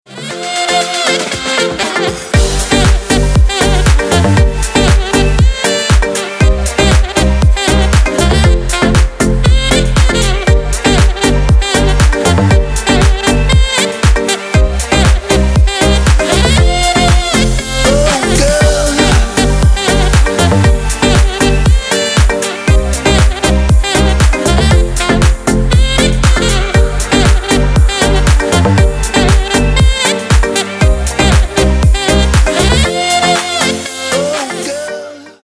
• Качество: 224, Stereo
dance
Electronic
EDM
club
Саксофон
танцевальные